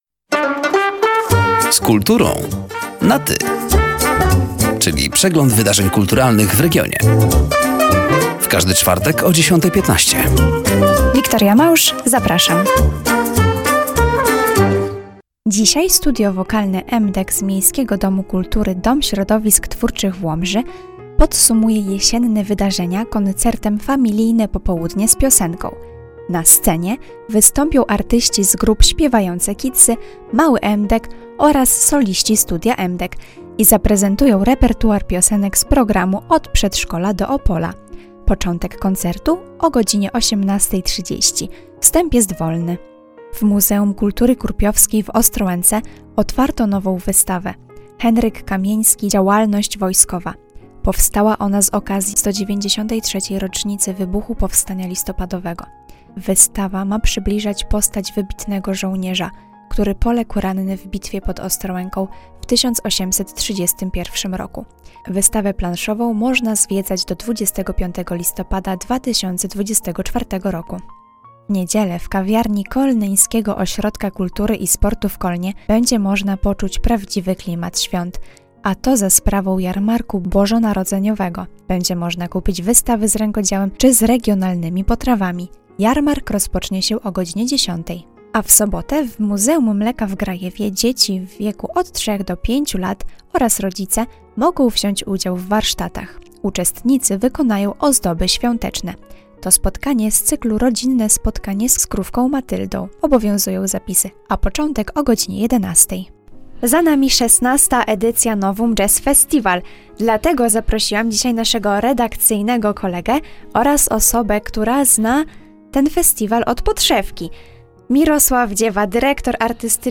Zapraszamy do zapoznania się ze zbliżającymi się wydarzeniami oraz do wysłuchania rozmowy.